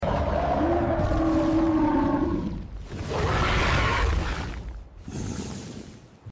With a final, violent lurch, the serpent opens its massive jaws in a deafening, guttural roar, fangs glistening, breath steaming, filling the frame in an uncomfortably intimate close-up that feels like it's about to burst from the screen.